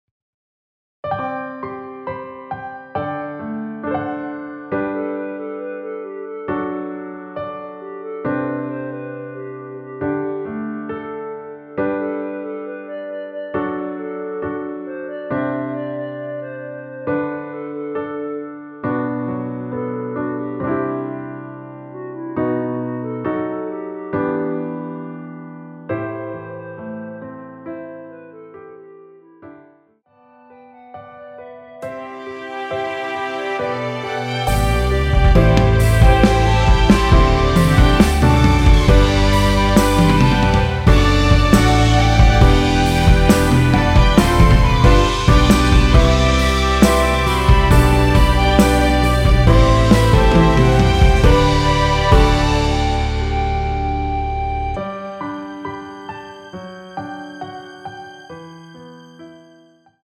원키에서(+4)올린 멜로디 포함된 MR입니다.(미리듣기 확인)
멜로디 MR이라고 합니다.
앞부분30초, 뒷부분30초씩 편집해서 올려 드리고 있습니다.
중간에 음이 끈어지고 다시 나오는 이유는